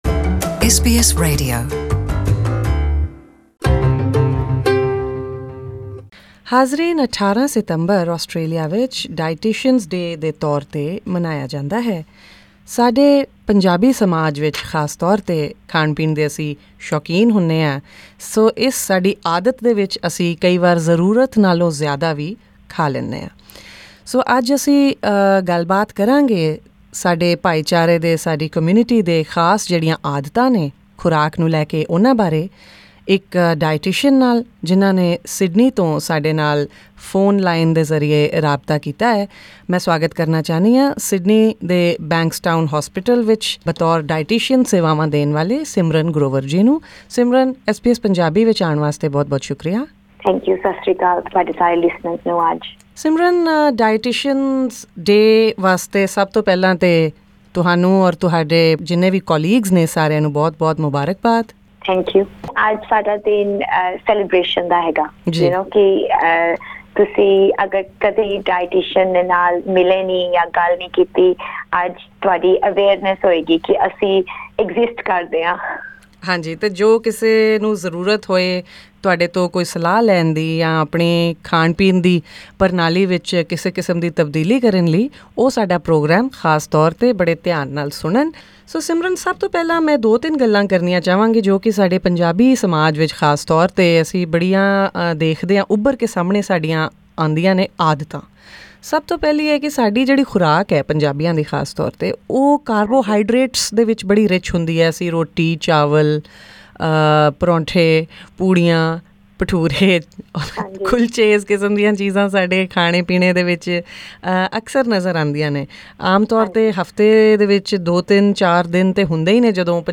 Listen to the complete interview in Punjabi.